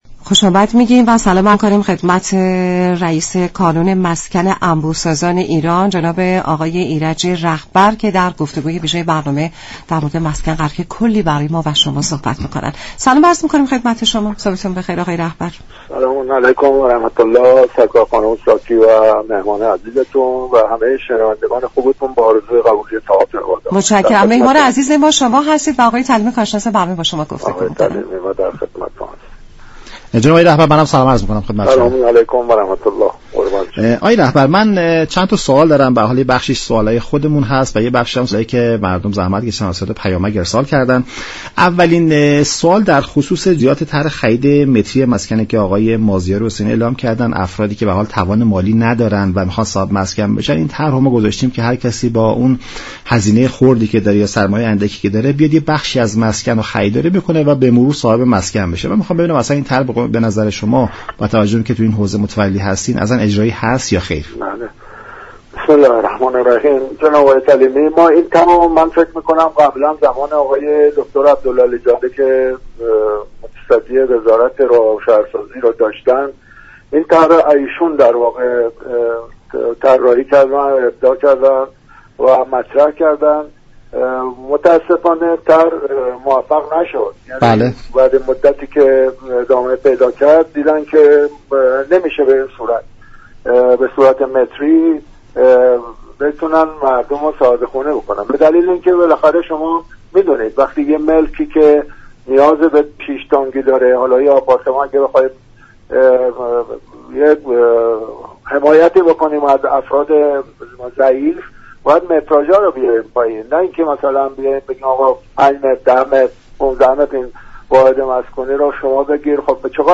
برنامه "نمودار" شنبه تا چهارشنبه هر هفته ساعت 10:30 از رادیو ایران پخش می شود. این گفت و گو را در ادامه می شنوید.